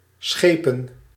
A schepen (Dutch, pronounced [ˈsxeːpə(n)]